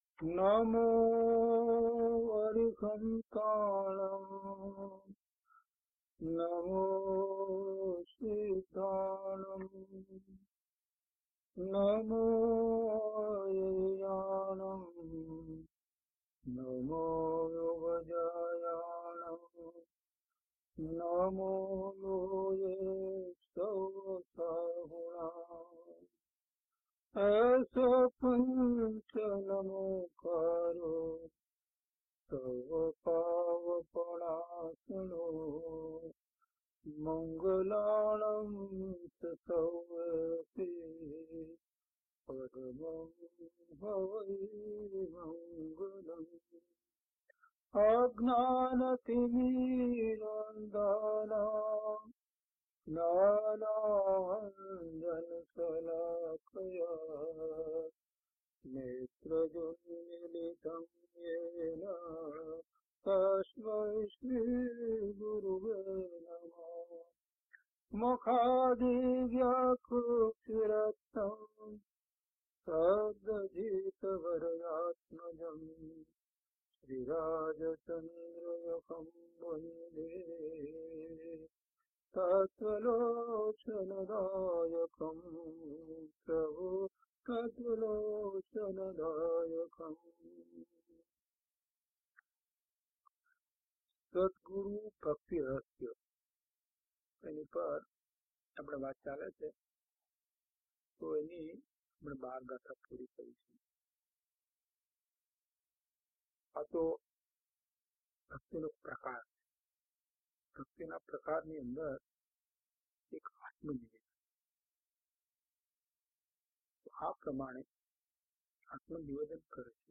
Audio title: DHP005 He Prabhu He Prabhu Gatha 13 to 20 - Pravachan.mp3